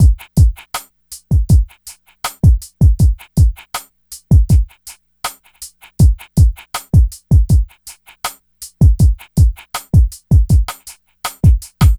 02C-DRM-80-L.wav